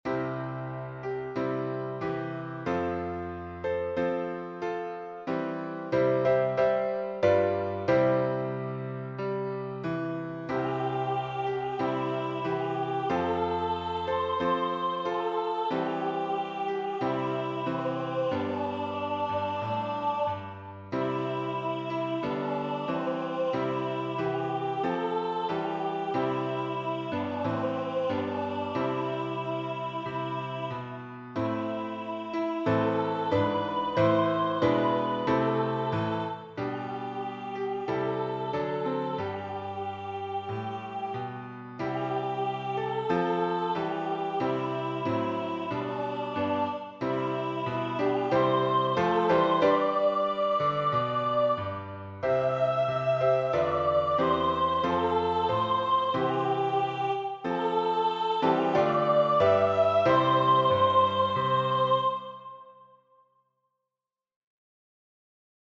メロディ